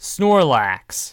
Snorlax (/ˈsnɔːrlæks/